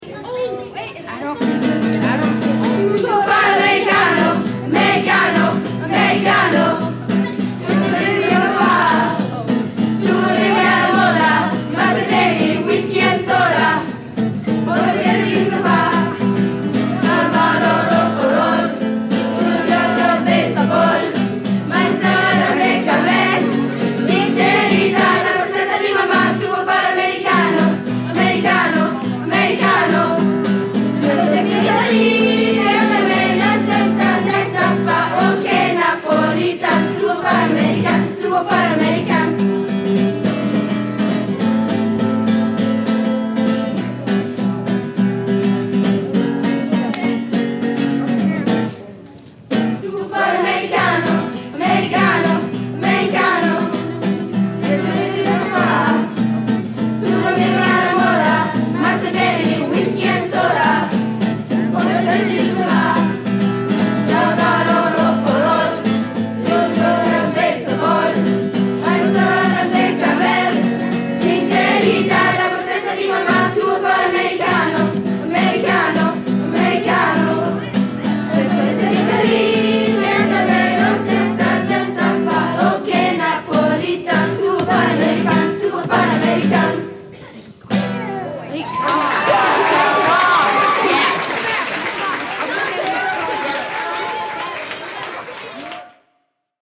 Winterfest 2016 Middle School Americano Song – Ecole Bilingue WebRadio